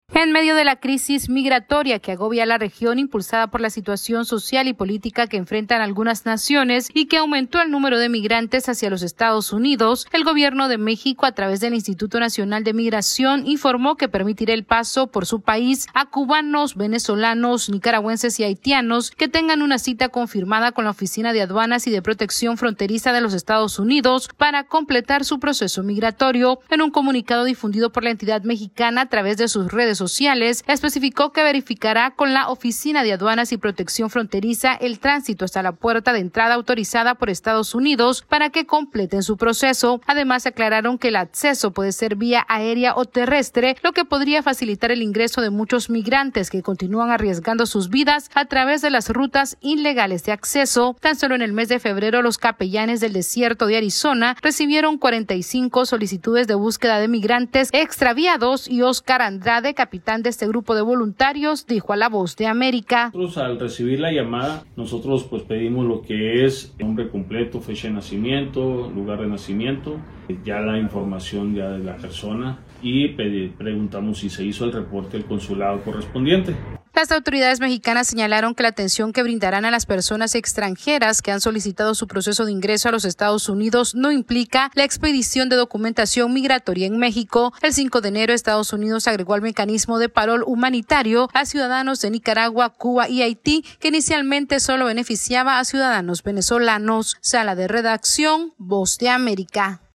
México permitirá el tránsito de ciudadanos venezolanos, cubanos, nicaragüenses y haitianos que tengan una cita confirmada con autoridades estadounidenses. Esta es una actualización de nuestra Sala de Redacción...